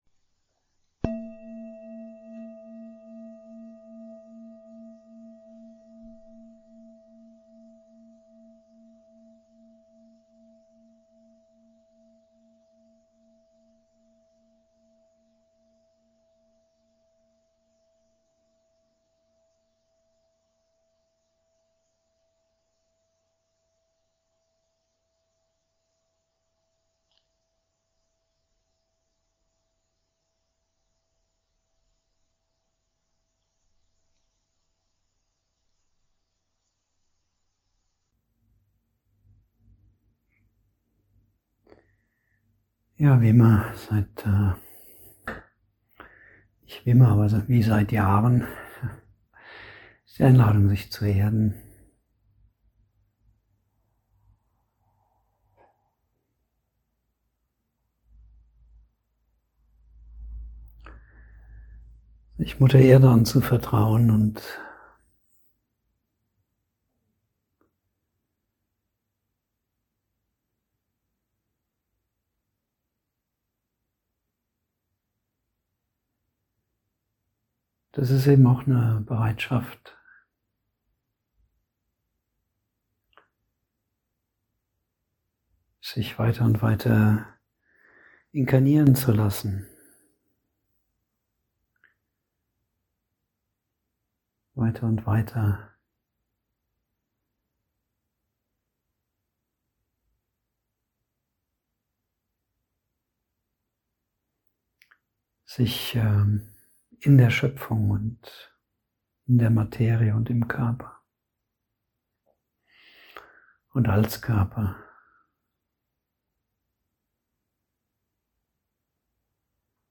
04 Kosmische und personale spirituelle Energie (Meditation Live-Mitschnitt)   33min